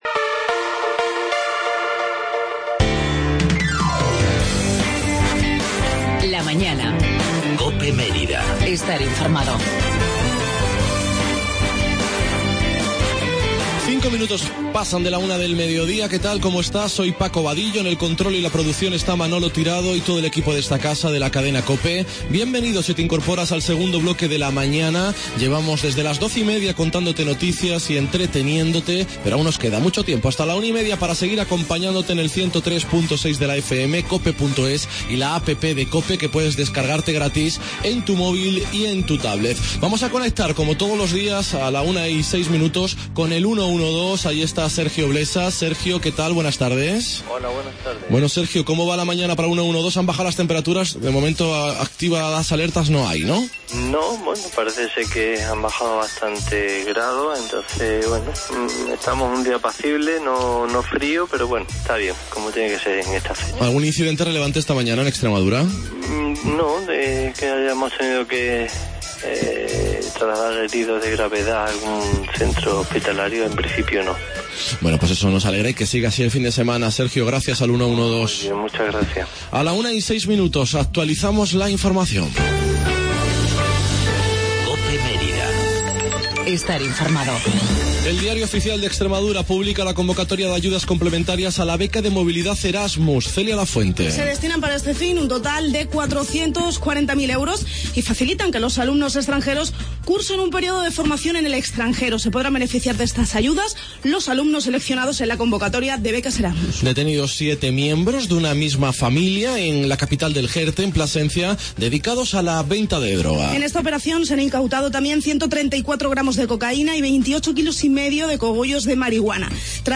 TERTULIA EUROCOPE - LA MAÑANA COPE MÉRIDA 10-06-16